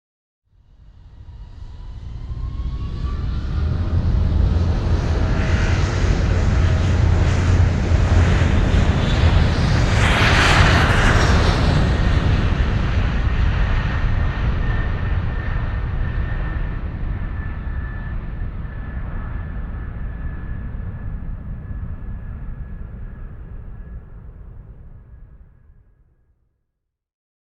На этой странице собраны реалистичные звуки двигателя самолета: от плавного гула турбин до рева при взлете.
Шум двигателей самолета в полете